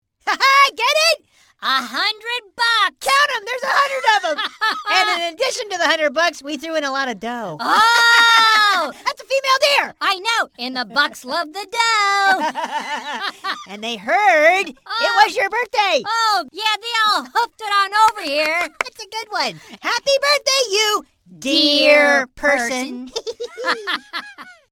100 Bucks is a hoops&yoyo pop-up greeting card with sound made for birthdays.
Card sound